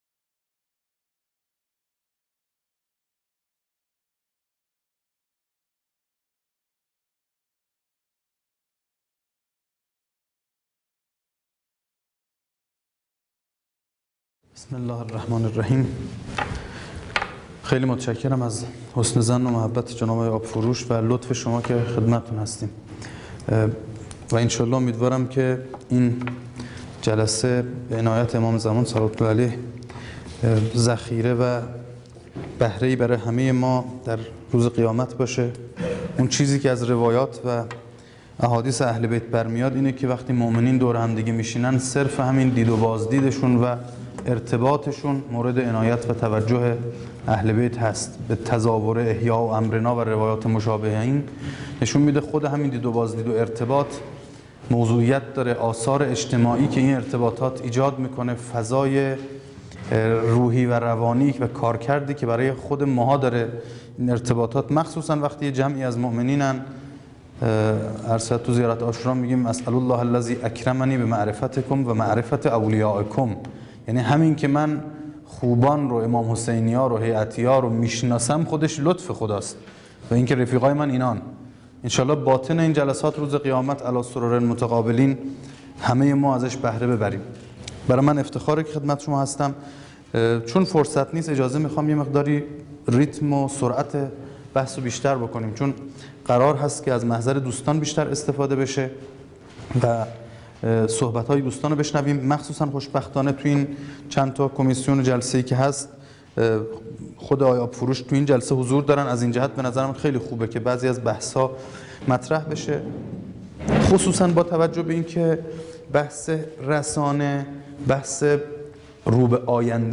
سخنرانی
بررسی ظرفیت‌های رسانه ای هیأت | دومین همایش ملی هیأت‌های محوری و برگزیده کشور